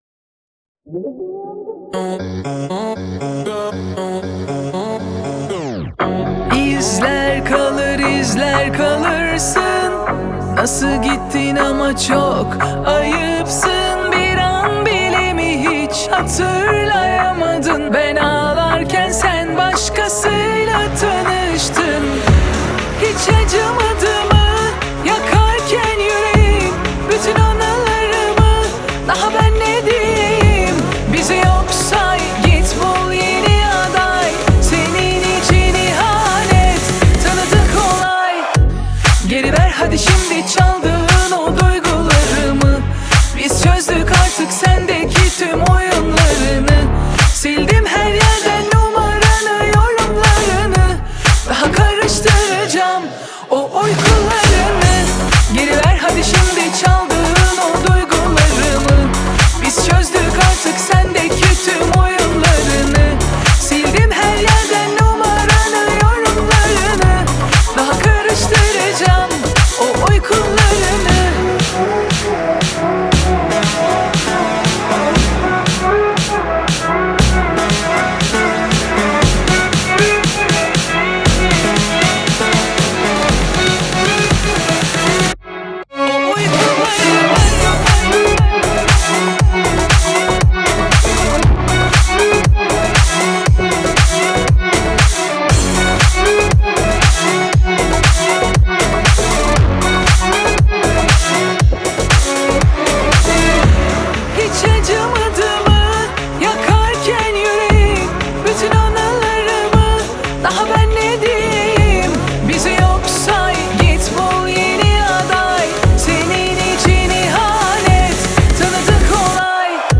588 بازدید ۳ اردیبهشت ۱۴۰۳ آهنگ , آهنگ های ترکی